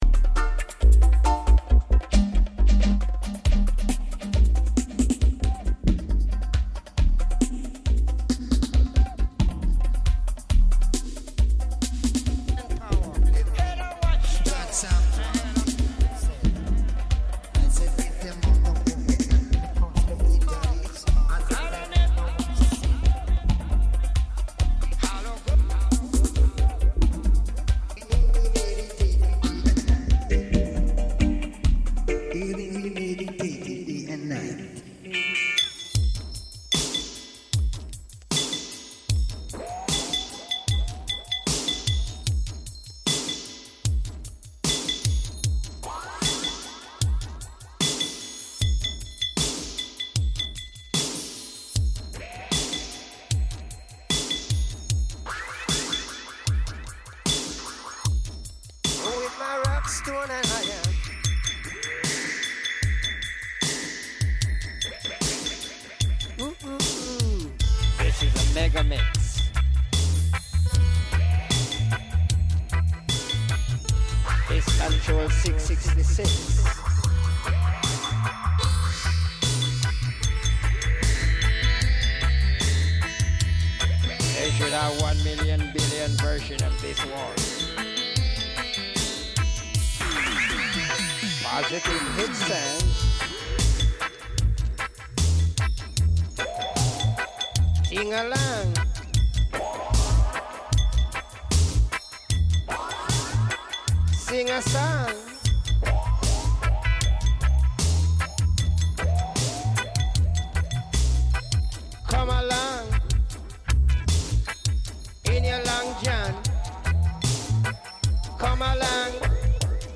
Lee Scratch Perry Interview on WTSQ (Part 2 of 2)